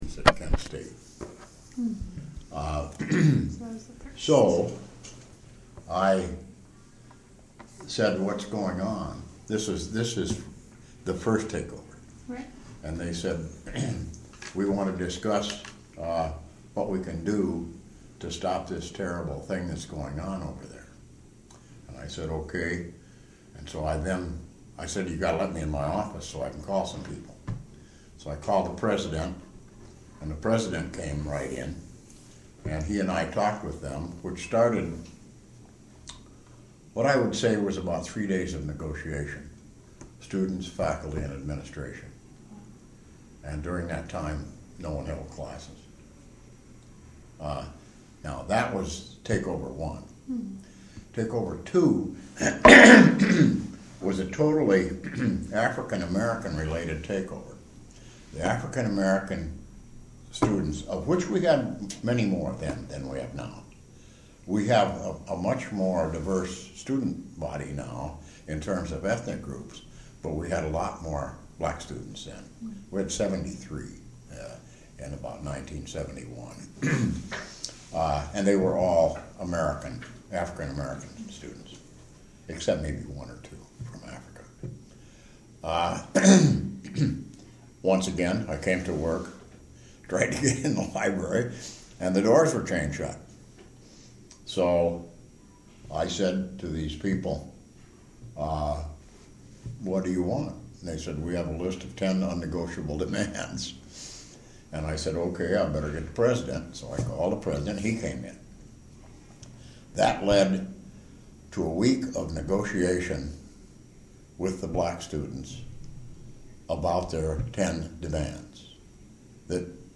An oral history interview